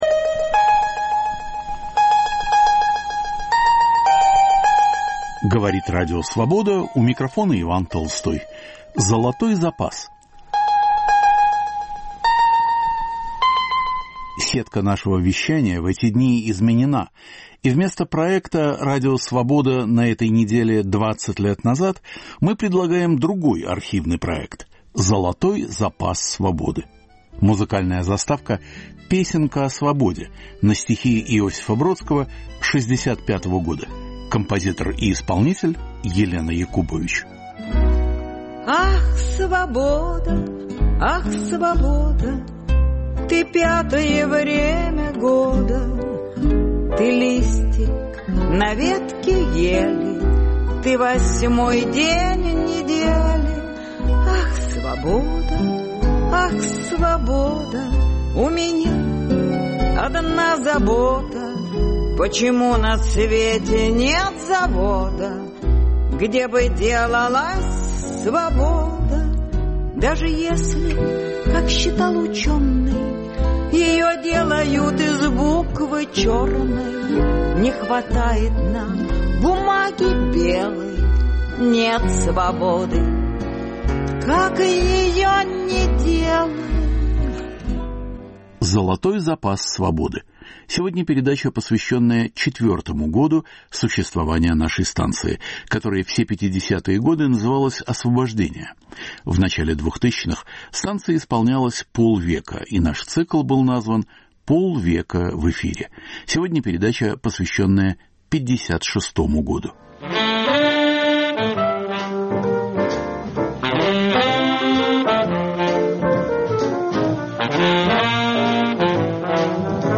Передача из цикла, посвященного 50-летию Радио Свобода. Год 1956 по архивным передачам: XX съезд, мода на русский язык, голливудский фильм "Война и мир".